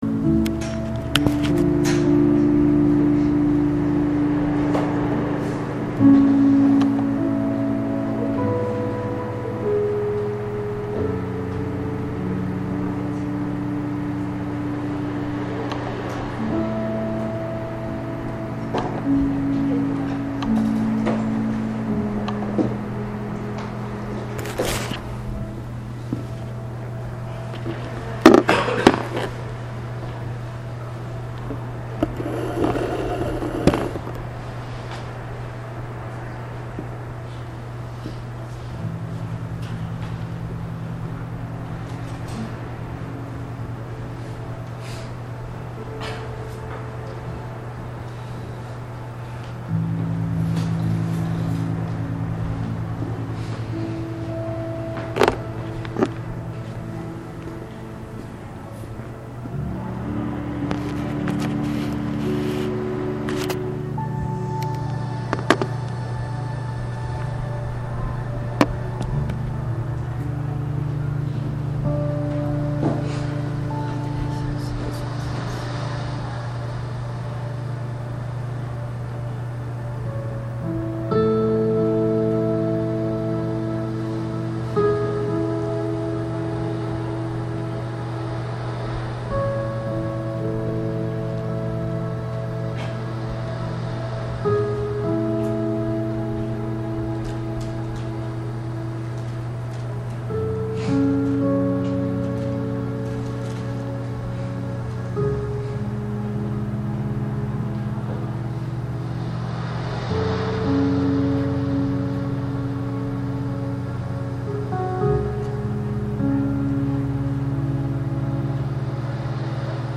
正在播放：--主日恩膏聚会录音（2016-01-10）